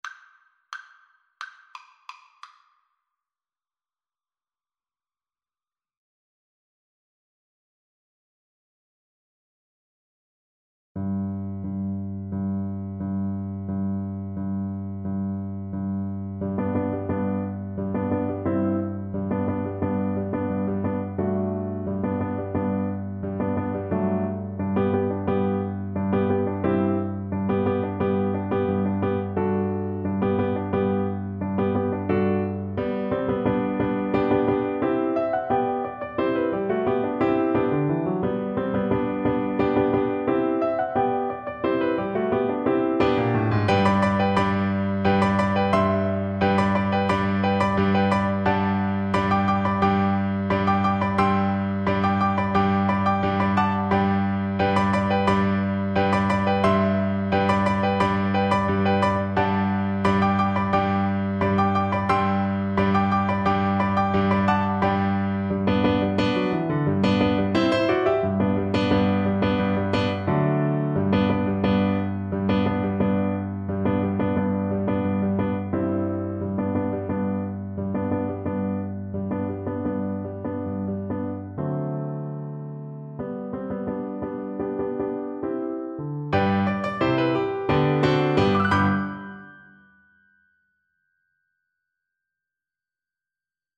Traditional Trad. Cotton-Eyed Joe Soprano (Descant) Recorder version
Recorder
Allegro Energico = c.88 (View more music marked Allegro)
G major (Sounding Pitch) (View more G major Music for Recorder )
2/2 (View more 2/2 Music)
C6-G7
Traditional (View more Traditional Recorder Music)